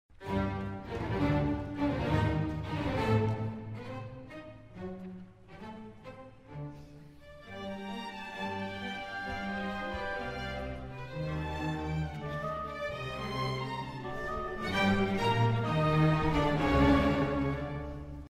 The movement has a lot of contrasting light and shade but tends towards the dramatic, right from its unison opening.
Example 6 – Opening of finale: